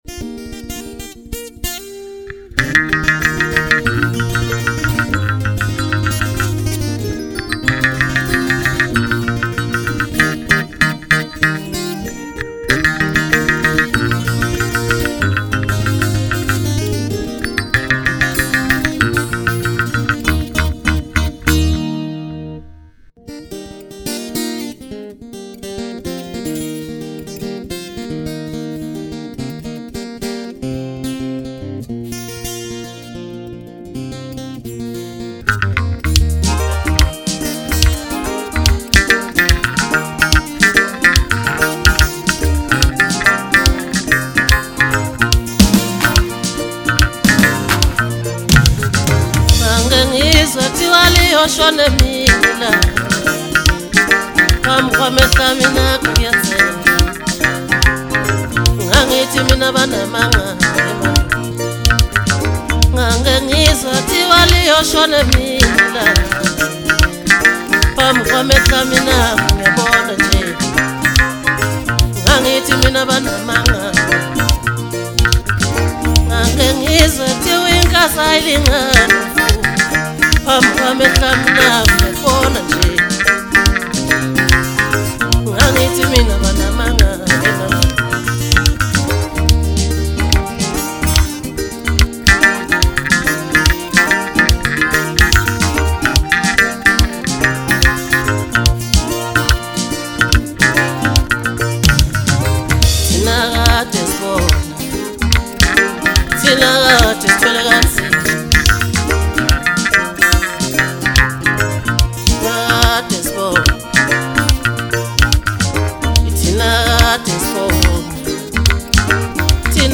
MASKANDI MUSIC